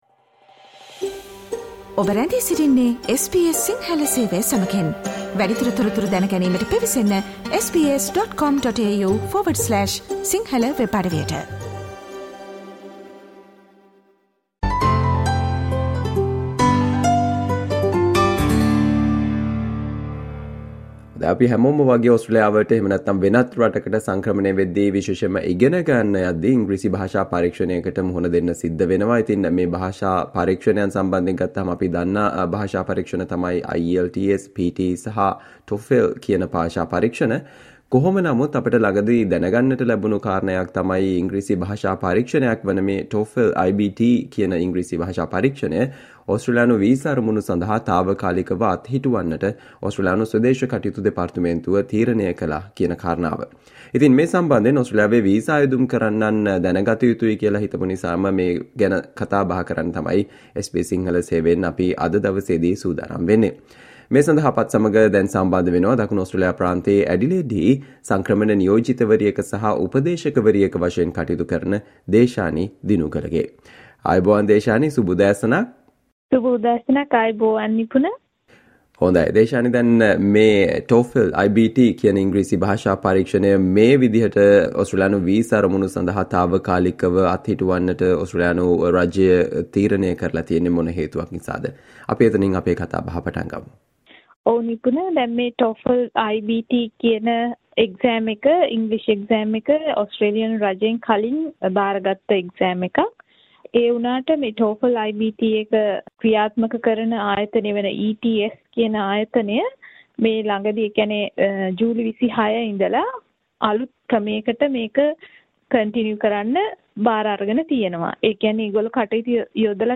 SBS Sinhala discussion on Why the English language test TOEFL iBT temporarily unavailable for Australian migration visa purposes?